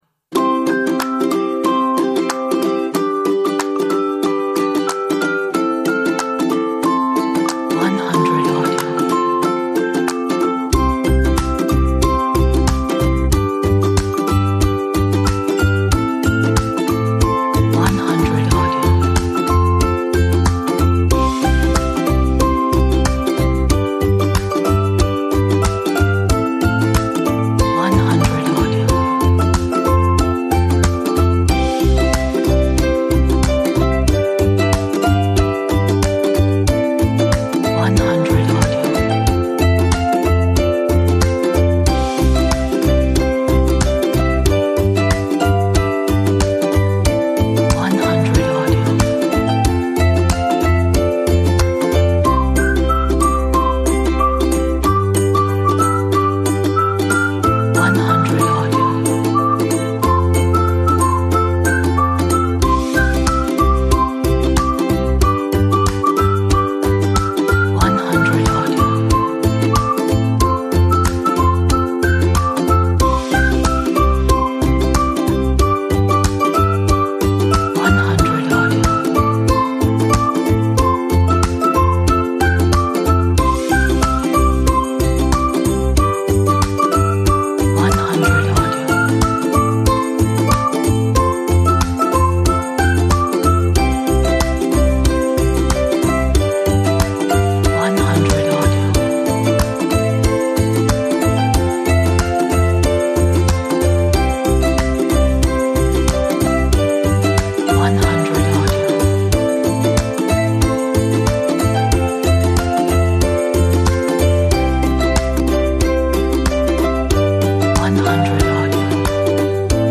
Upbeat track with great cheerful energy.